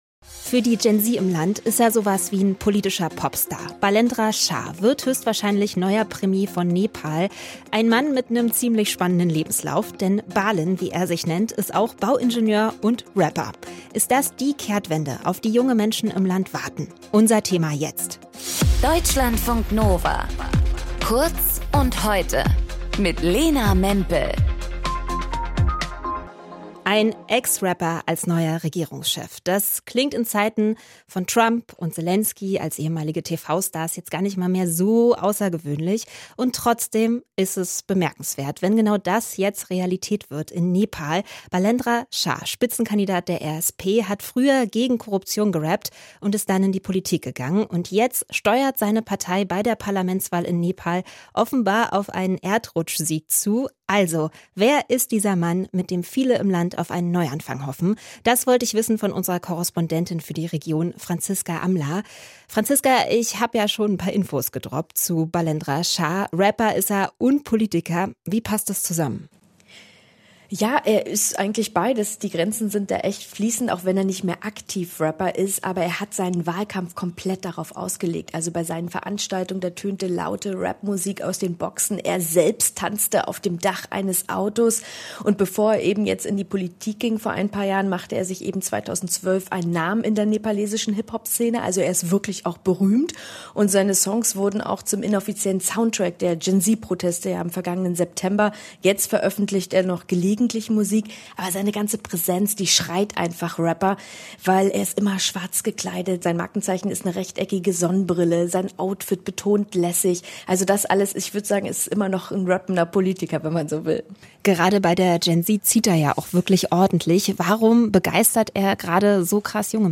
In dieser Folge mit:
Moderation:
Gesprächspartnerin: